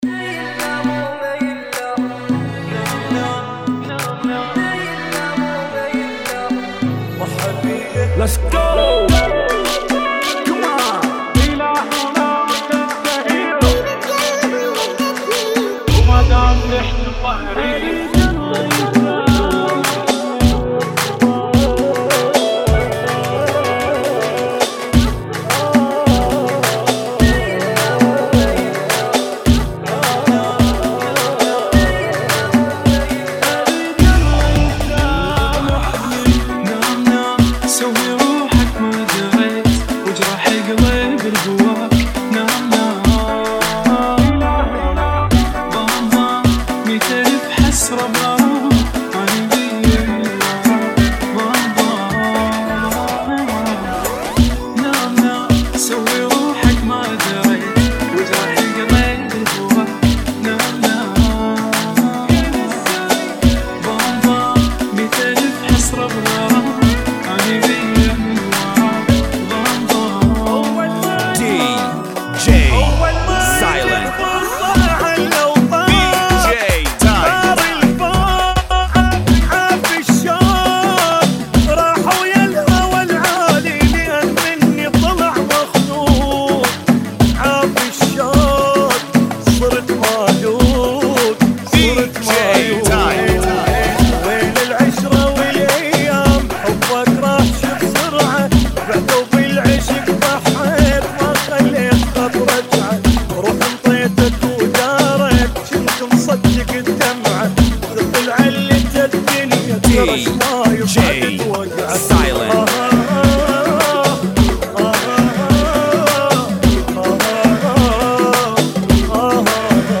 REMIX 2018 - ريمكس